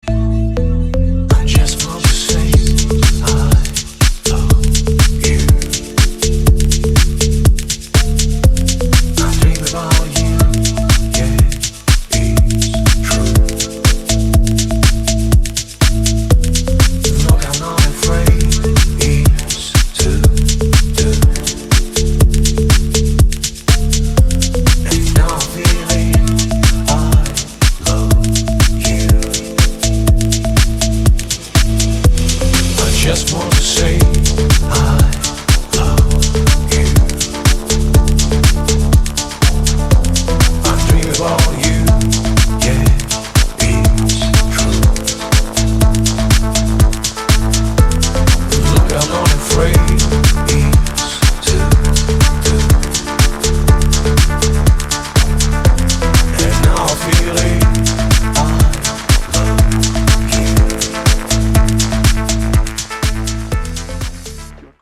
• Качество: 320, Stereo
мужской голос
Electronic
спокойные
романтичные
шепот
Стиль: deep house